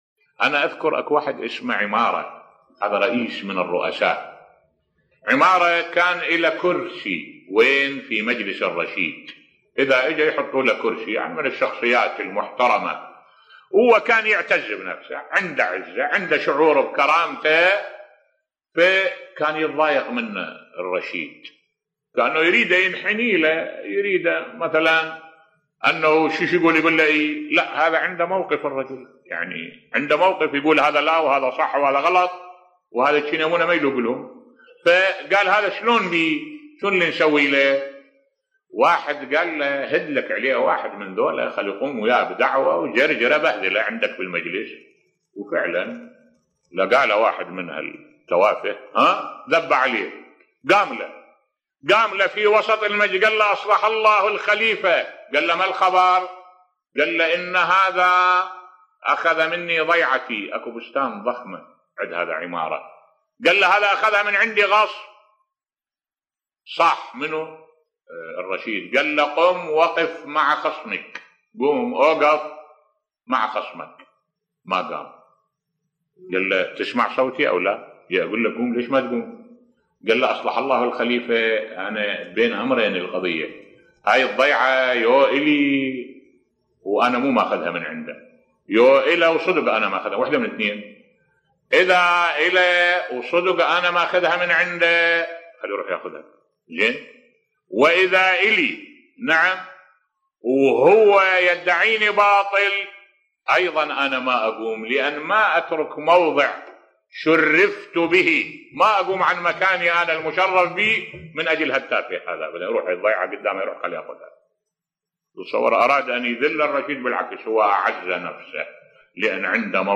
ملف صوتی عزة النفس (قصة و عبرة) بصوت الشيخ الدكتور أحمد الوائلي